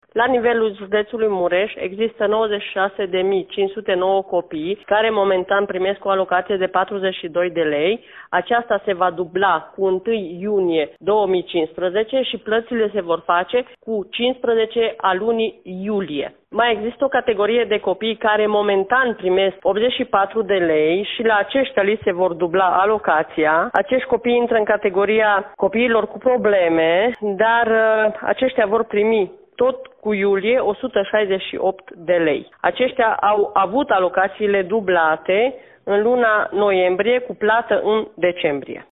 Directorul Agenției Județene pentru Plăți și Inspecție Socială, Semina Baricz.